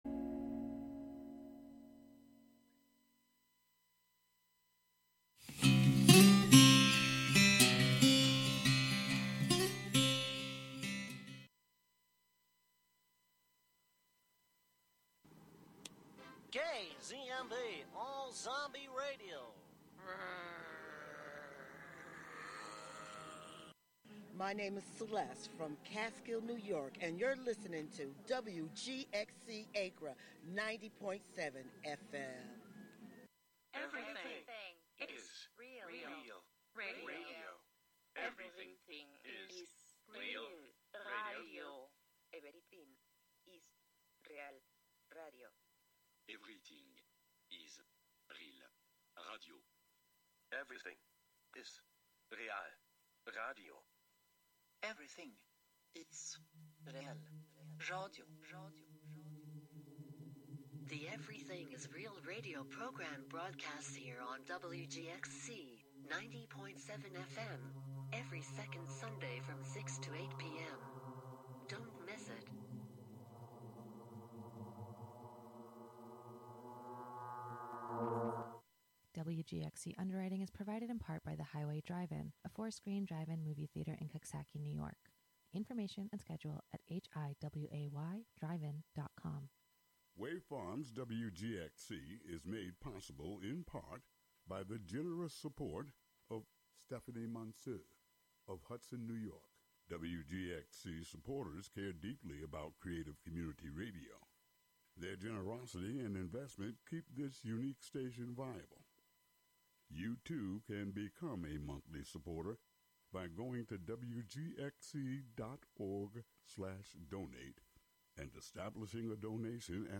The show also features a special Happy Father’s Day “Jeopardy-style” roundtable game that flips the script in a fun-filled, informative way.